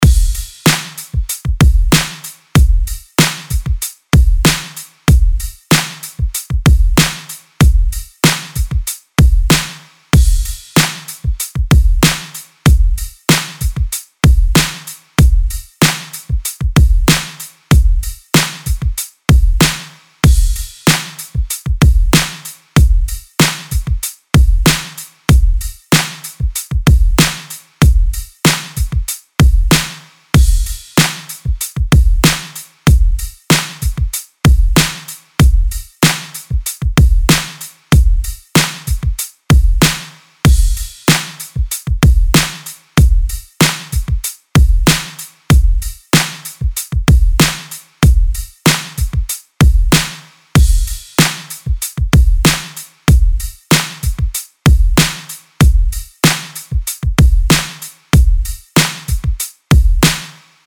LP 202 – DRUM LOOP – HIPHOP – 95BPM
LP-202-DRUM-LOOP-HIPHOP-95BPM.mp3